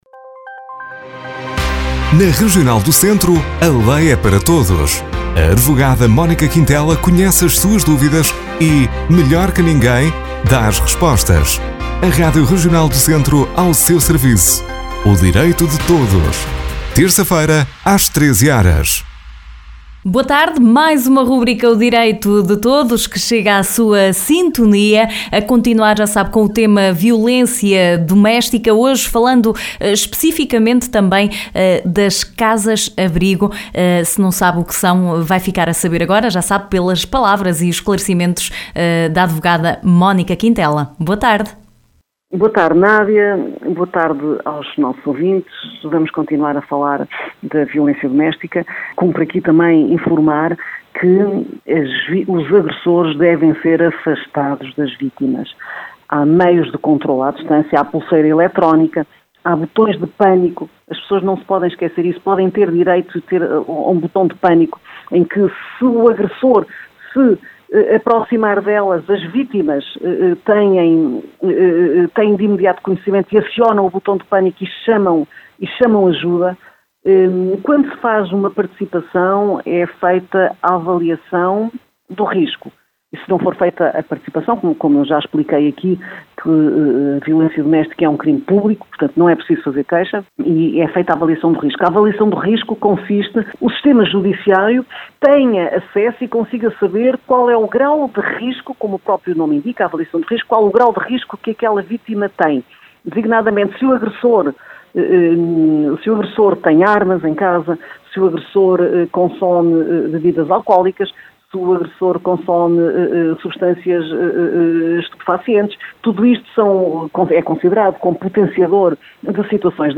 Continuamos na abordagem ao tema ‘violência doméstica’, pela advogada Mónica Quintela.